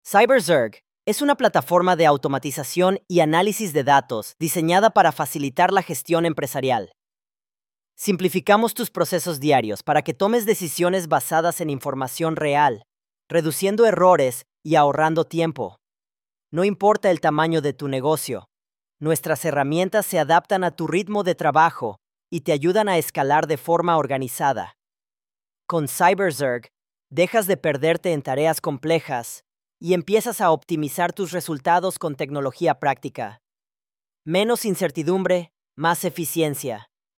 Речь звучит взвешенно и ровно, без избыточного эмоционального окраса.
Это умеренный мужской голос с нейтральным произношением, который направляет внимание на информацию, а не на манеру исполнения.
Звуковая дорожка не имеет слышимых дефектов или программных артефактов.